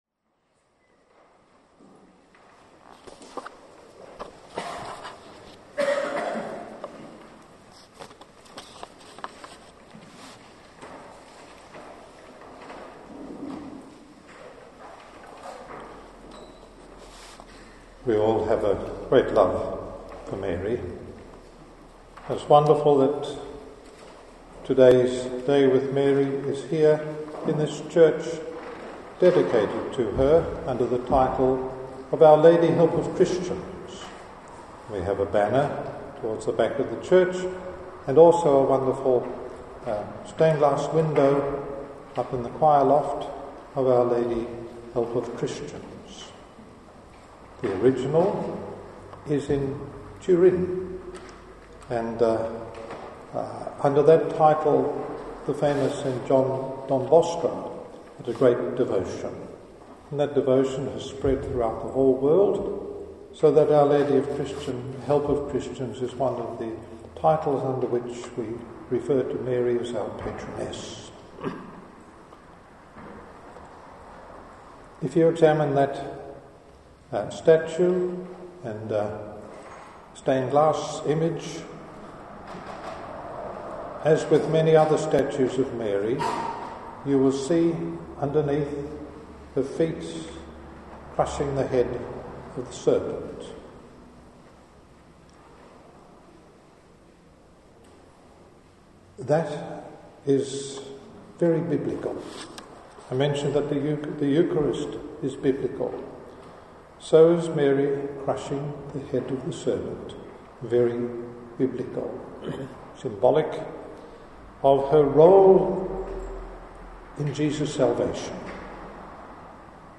Archbishop Barry James Hickey speaks on Our Lady at the “Day With Mary” held at Our Lady Help of Christians Parish, East Victoria Park, Perth, Western Australia on September 3, 2011.